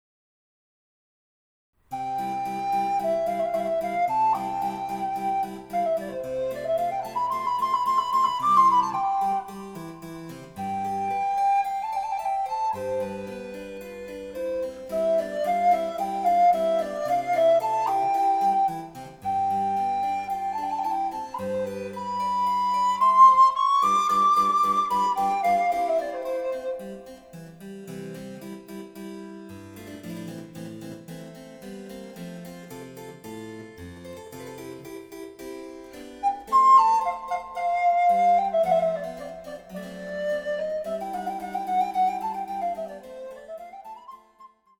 ■アルトリコーダーによる演奏
電子チェンバロ